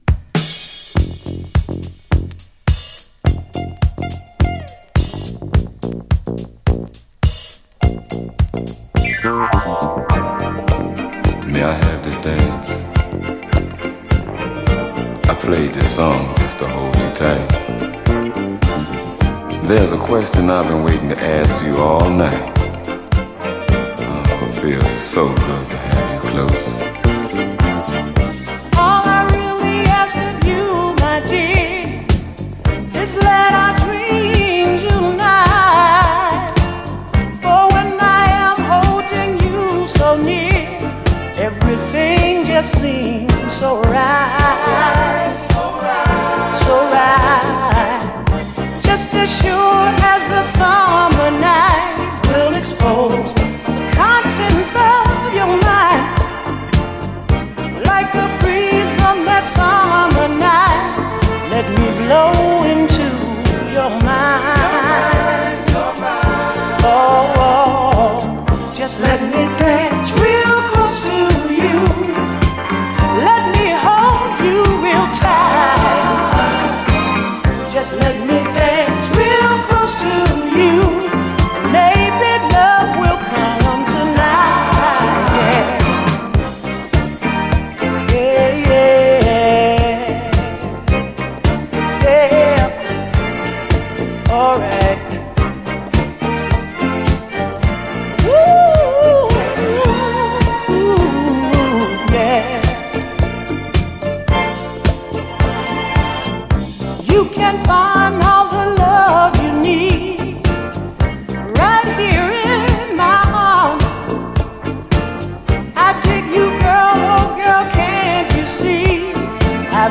SOUL, 70's～ SOUL
流麗ミディアム・ソウル